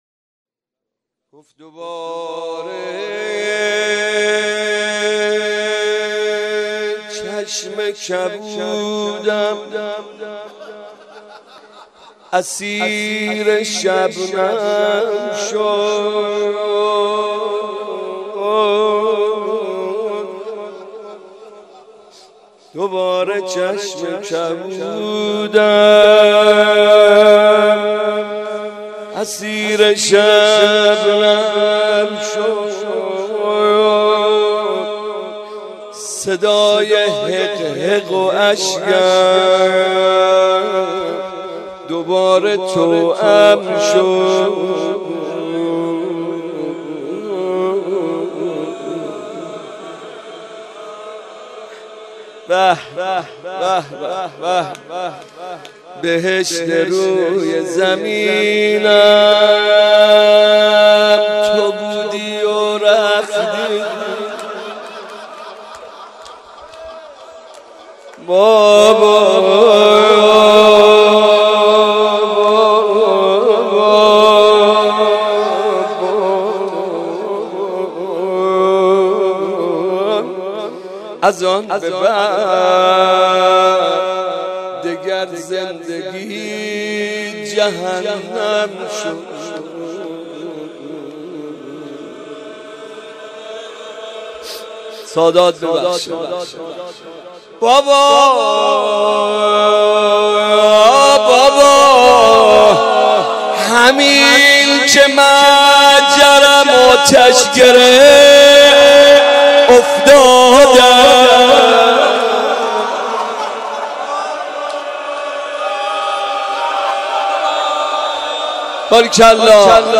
شب سوم رمضان المبارک 1394
دوباره چشم کبودم اسیر شبنم شد | روضه ی حضرت رقیه سلام الله علیها